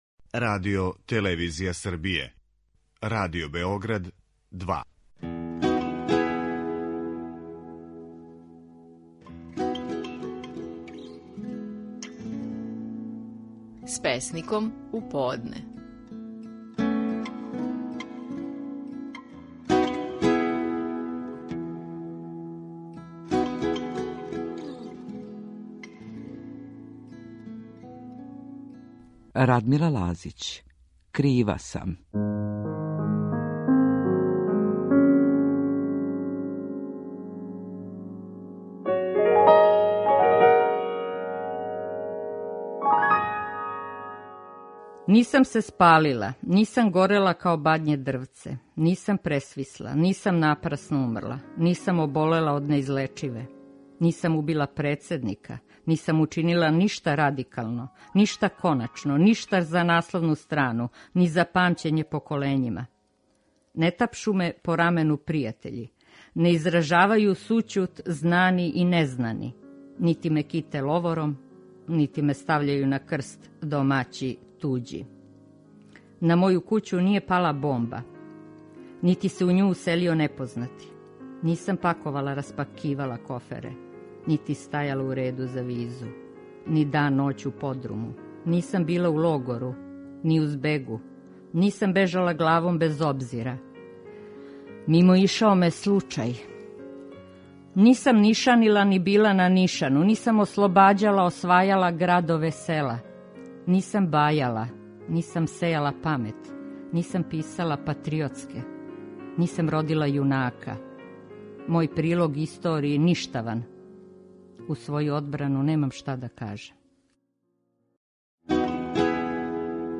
Стихови наших најпознатијих песника, у интерпретацији аутора.
Радмила Лазић говори своју песму „Kрива сам".